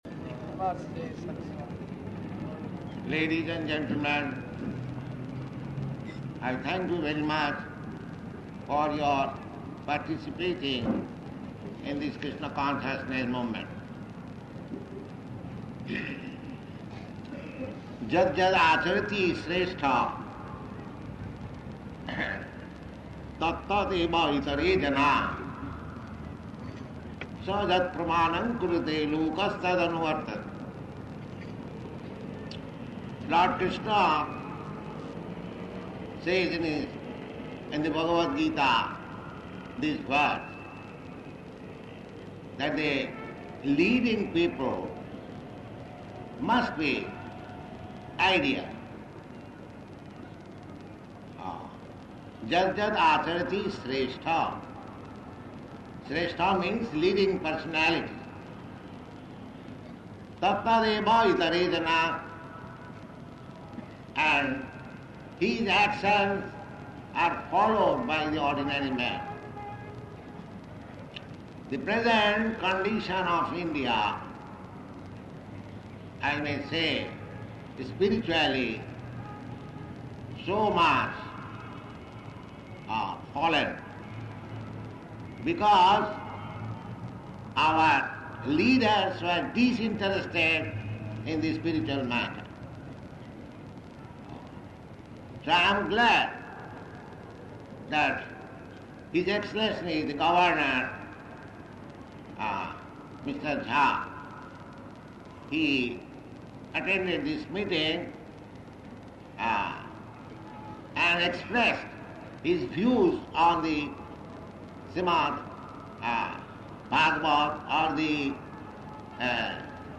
Paṇḍāl Lecture
Type: Lectures and Addresses
Location: Delhi